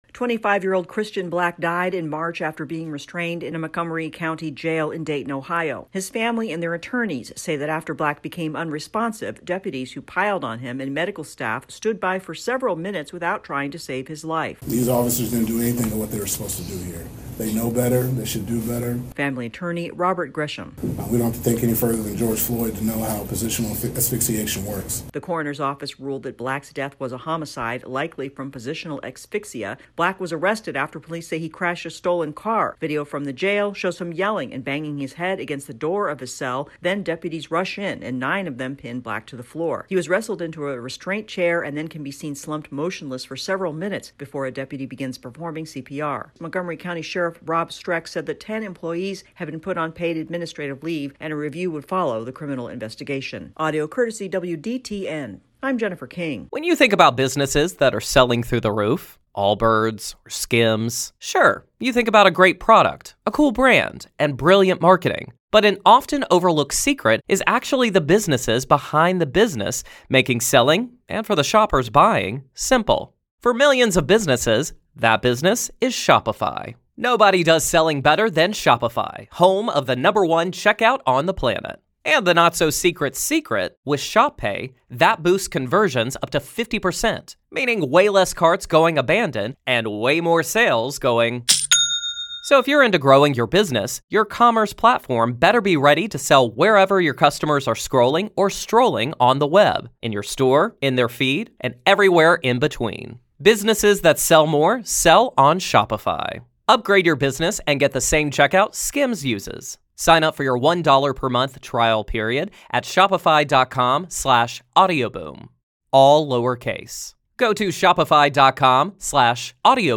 The family of an Ohio man say deputies and jail medical staff should be criminally charged in his death. AP correspondent